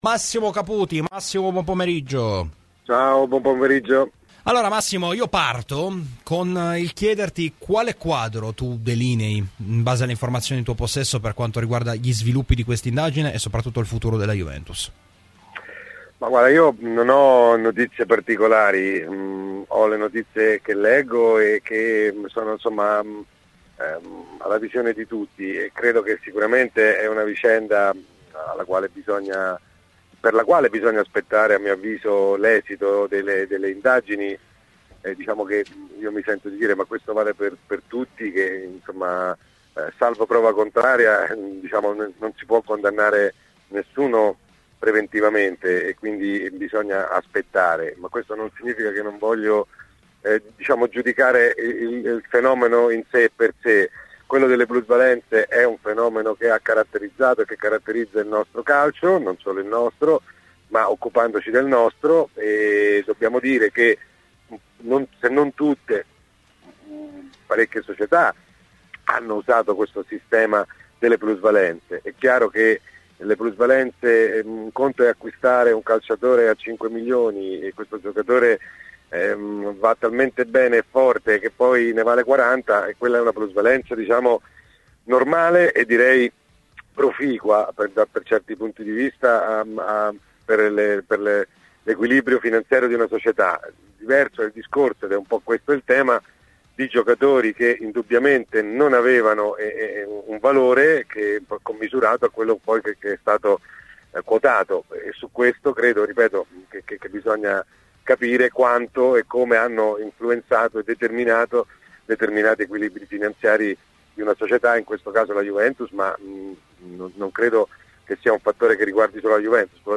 L'intervento integrale nel podcast